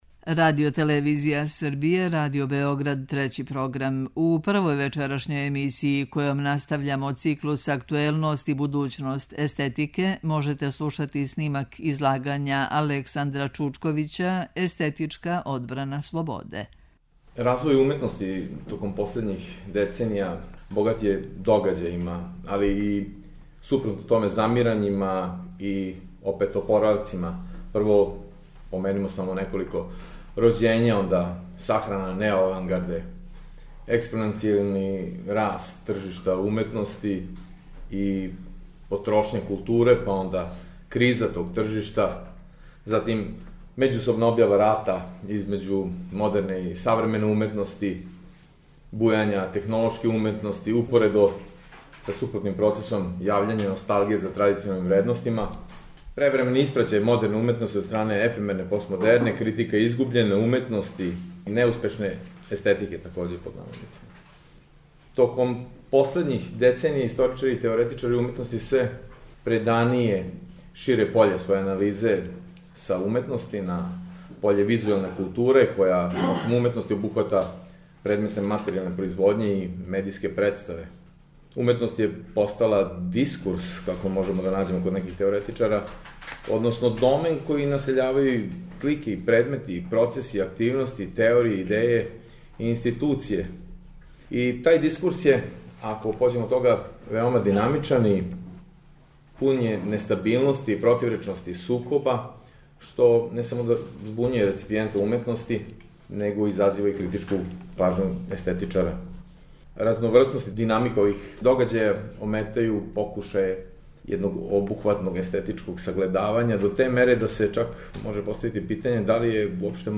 У две вечерашње емисије, којима настављамо циклус АКТУЕЛНОСТ И БУДУЋНОСТ ЕСТЕТИКЕ, можете пратити снимке излагања са истоименог научног скупа одржаног средином децембра 2014. у организацији Естетичког друштва Србије.
преузми : 8.01 MB Трибине и Научни скупови Autor: Редакција Преносимо излагања са научних конференција и трибина.